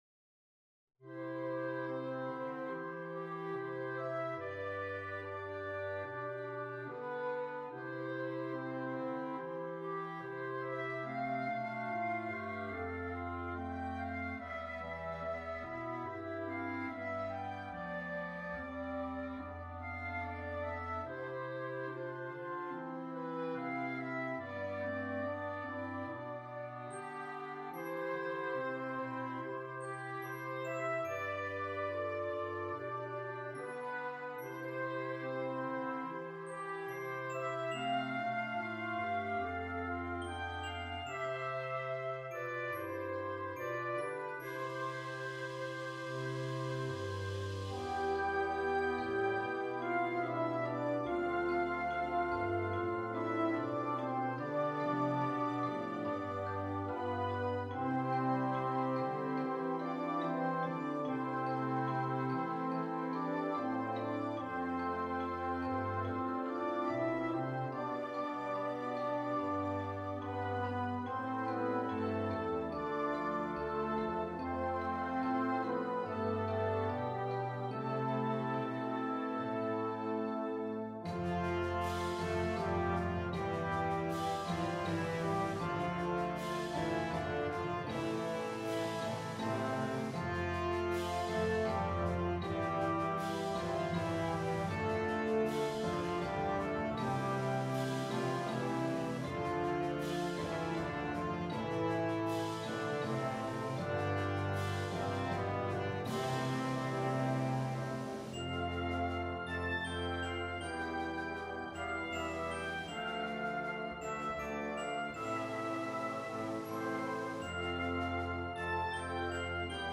Slow with a “Con Moto” in the middle.